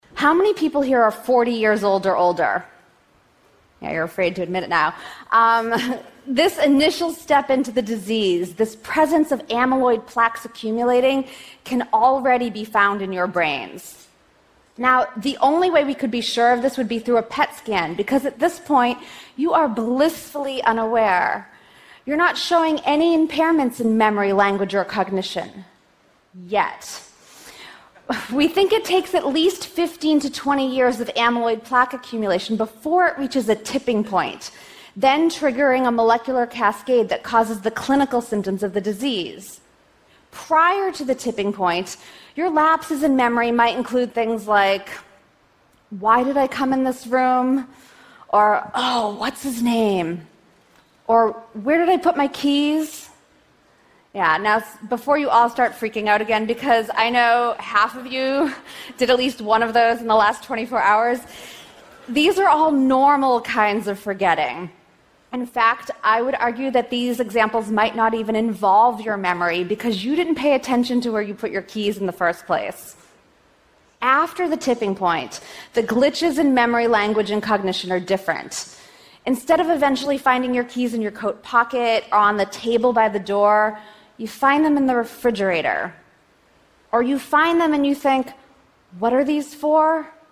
TED演讲:和老年痴呆症说再见(3) 听力文件下载—在线英语听力室